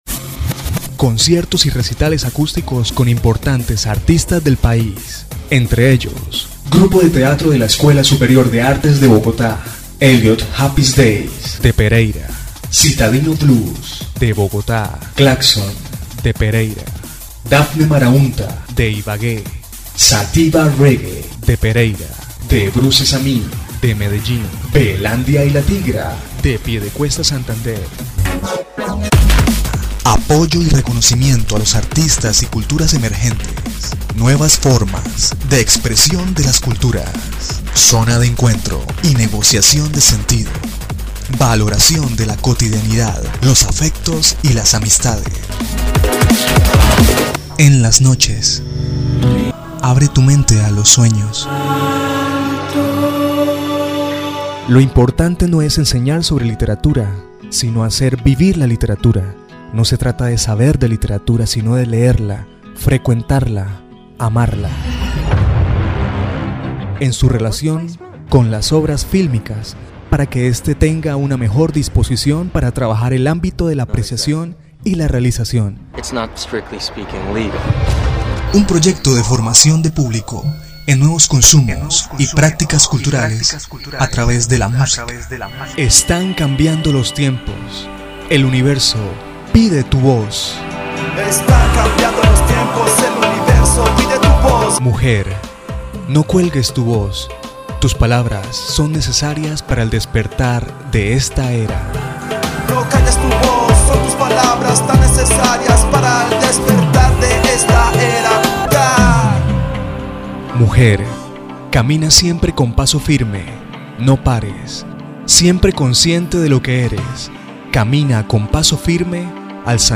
kolumbianisch
Sprechprobe: Werbung (Muttersprache):